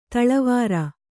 ♪ taḷavāra